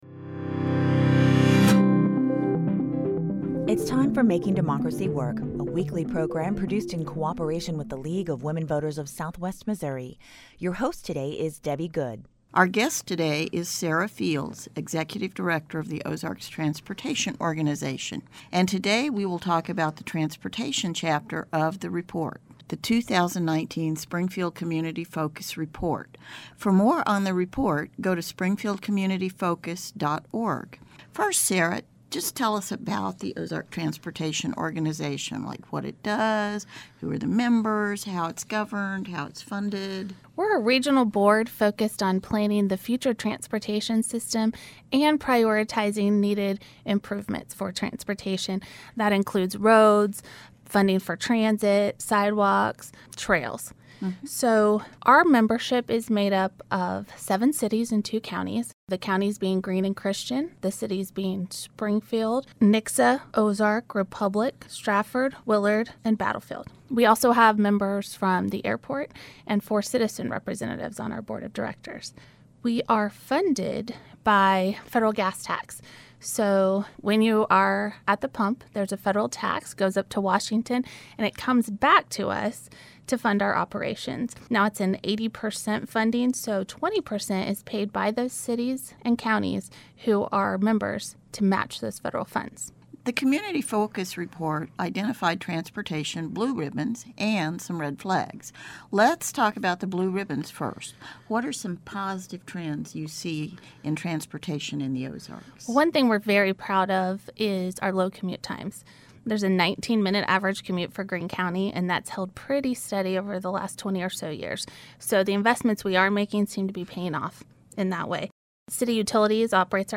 This week on Making Democracy Work, we continue our ongoing series looking deeper into individual aspects of the 2019 Springfield-Greene County focus report.  Todays’ discussion explores Red Flags and Blue Ribbons related to transportation.